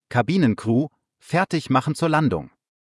CrewSeatsLanding.ogg